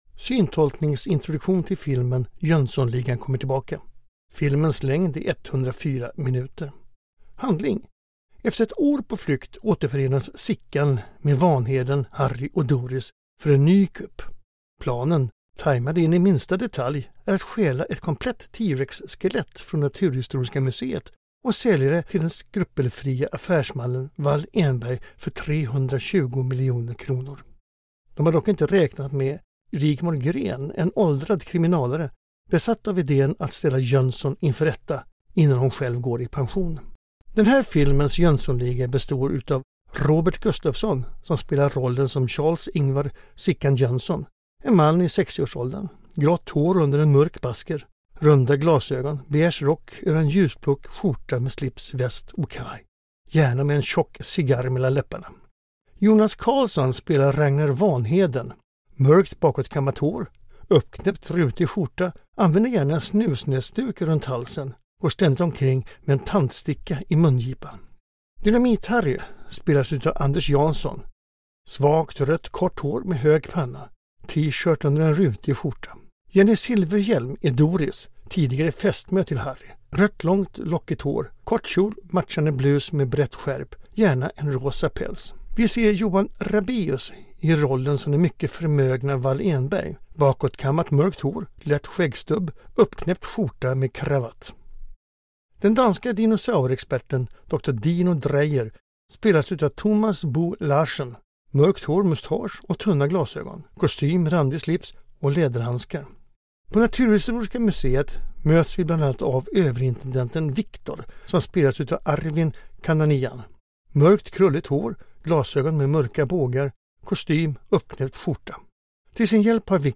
Syntolkningen finns på mobilappen för både bio och TV/streaming.
JONSSONLIGAN_trailer-file.mp3